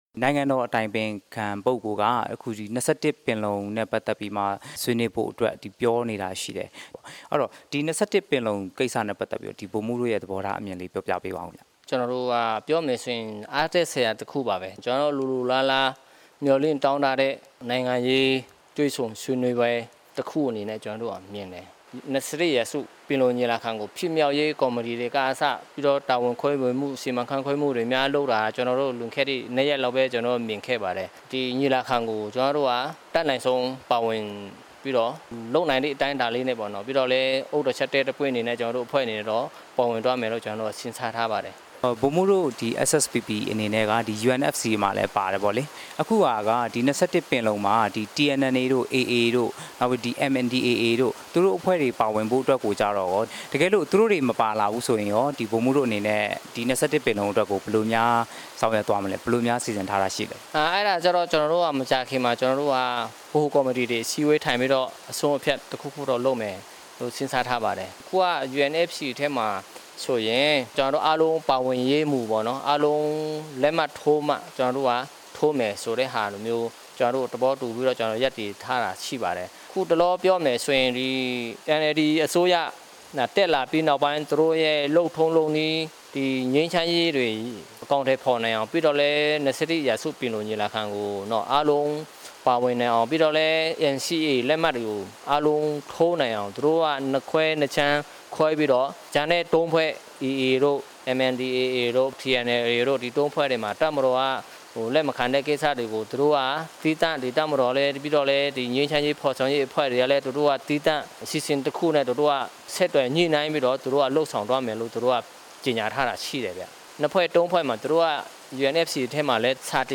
တွေ့ ဆုံမေးမြန်းထားပါတယ်။